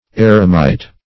Search Result for " eremite" : Wordnet 3.0 NOUN (1) 1. a Christian recluse ; The Collaborative International Dictionary of English v.0.48: Eremite \Er"e*mite\, n. [See Hermit .]